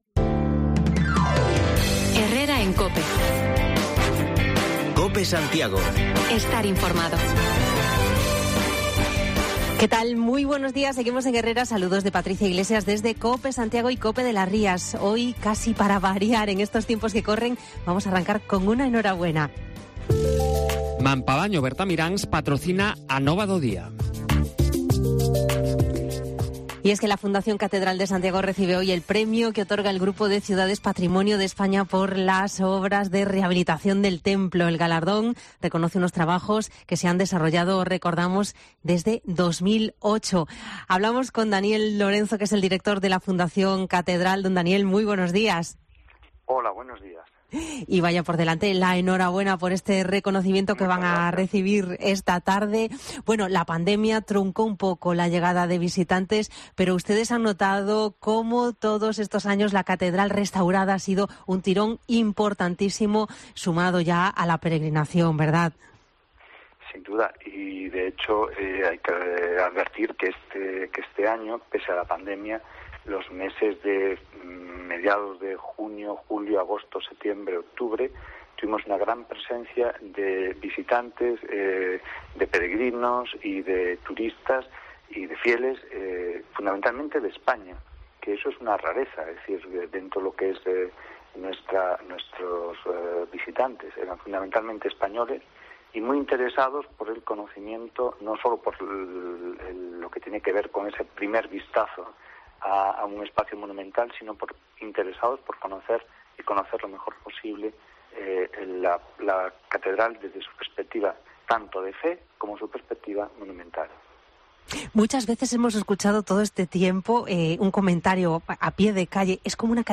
Actualizamos lo más destacado de la jornada, con entrevista